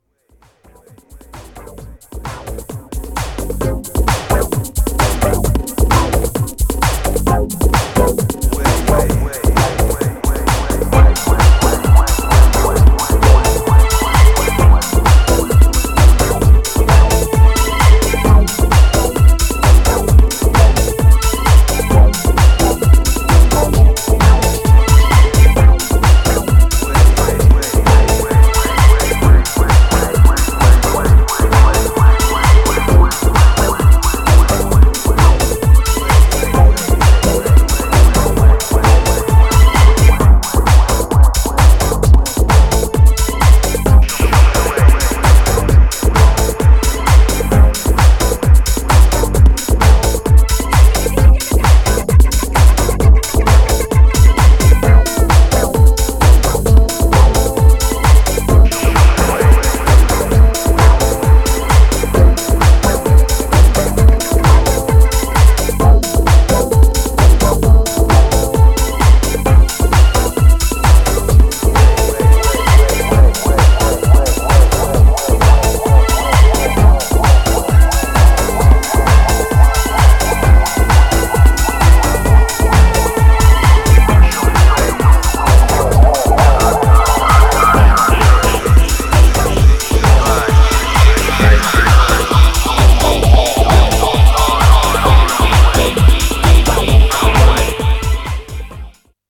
Styl: Progressive, House, Techno